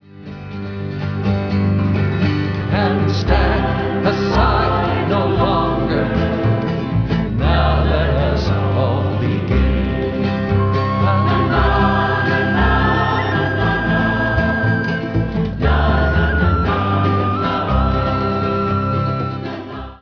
voice, 12-string guitar
6-string guitar
tin-whistle
bass
congas, triangle, shaker
chorus